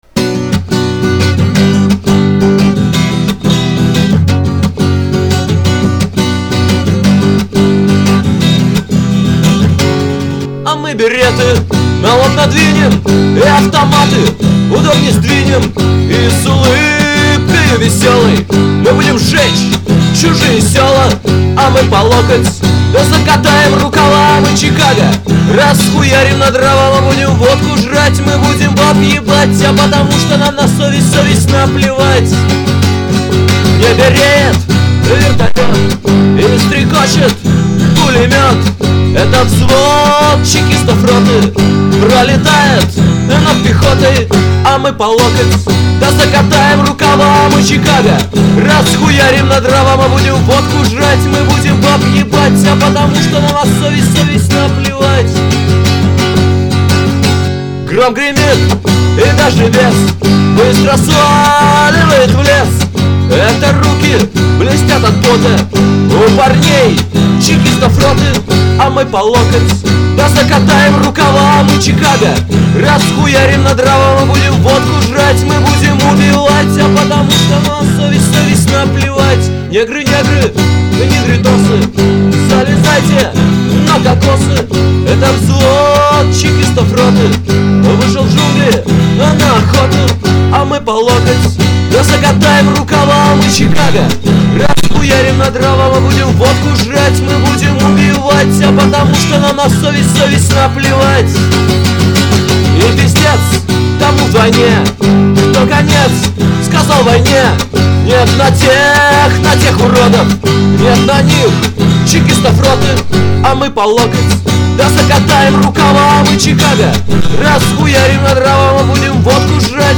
Категория: Песни под гитару